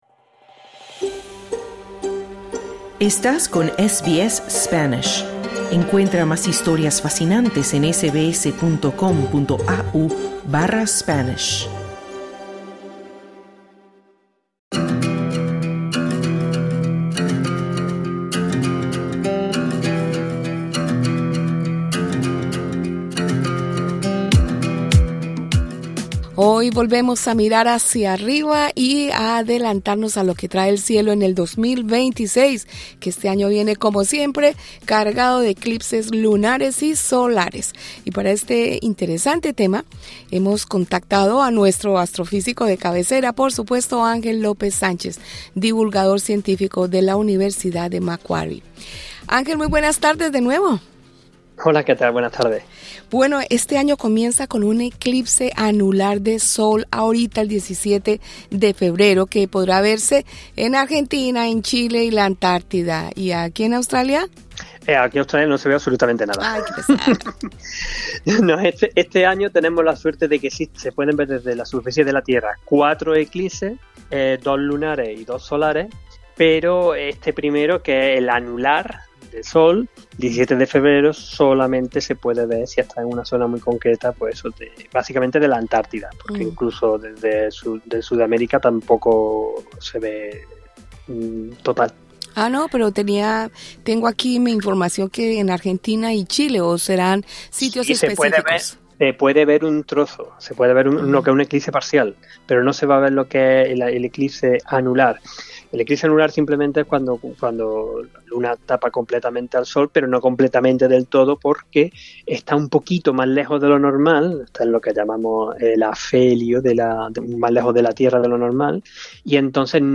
El 2026 será otro año espectacular para mirar el cielo: llega con un eclipse total de Luna en marzo —la famosa “Luna de sangre”—. Y nos ofrece un eclipse total de Sol en agosto, considerado el evento astronómico más importante del año. Escucha la entrevista con el astrofísico